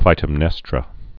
(klītəm-nĕstrə)